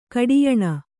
♪ kaḍiyaṇa